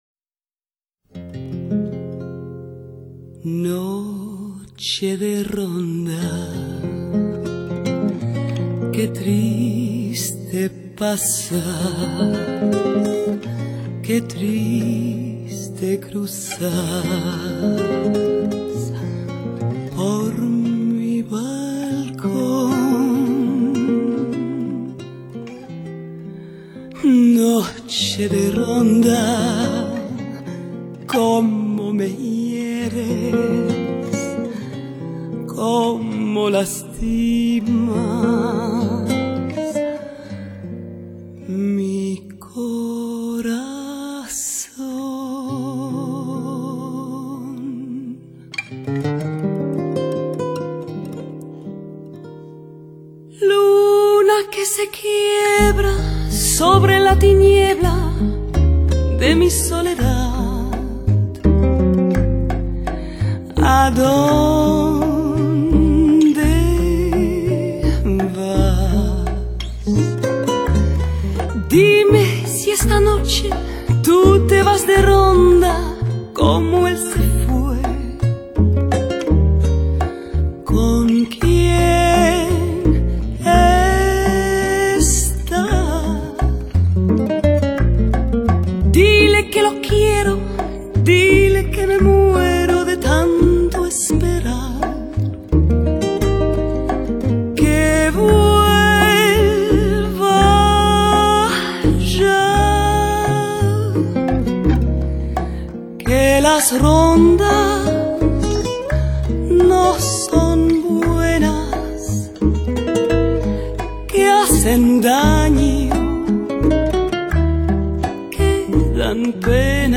拉丁风情的曼妙JAZZ……
平易近人的旋律搭配上她温暖轻松的唱腔，让歌迷们感到温暖贴心和舒适。
火热而纯正的伦巴、恰恰、莎莎、波列罗节奏